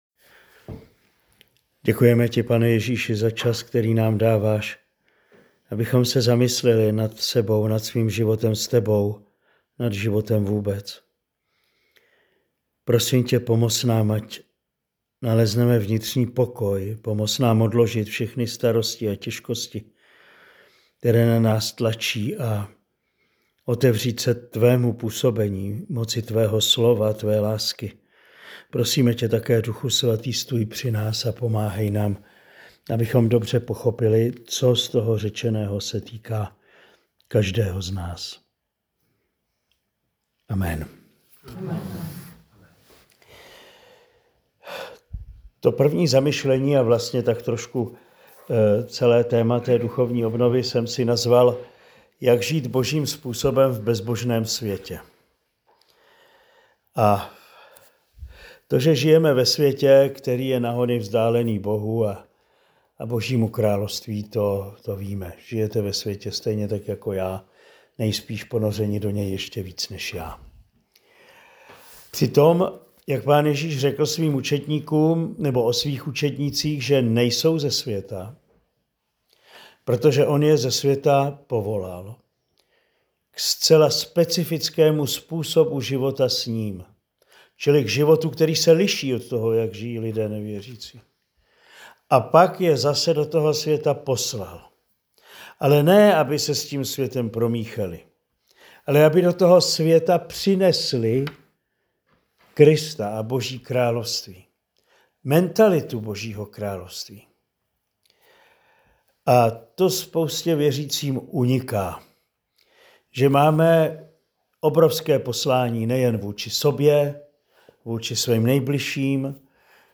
První promluva z duchovní obnovy pro manžele v Kostelním Vydří v únoru 2025.